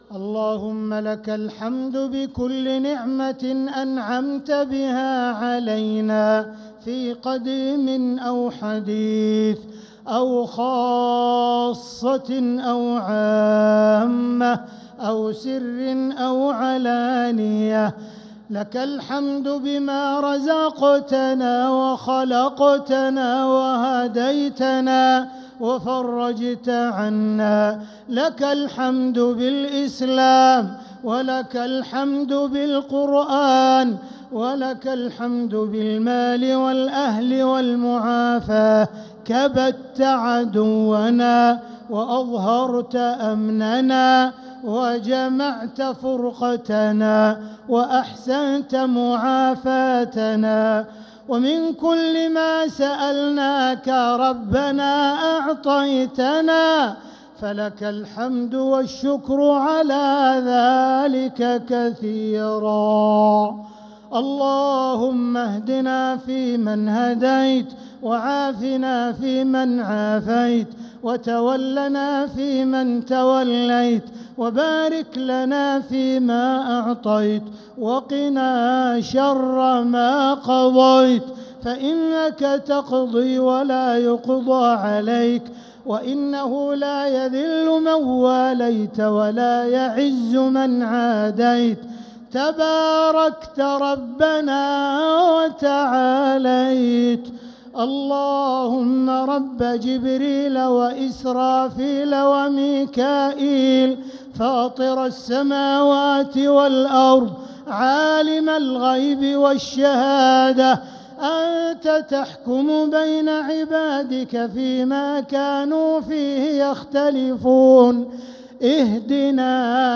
دعاء القنوت ليلة 19 رمضان 1446هـ | Dua 19th night Ramadan 1446H > تراويح الحرم المكي عام 1446 🕋 > التراويح - تلاوات الحرمين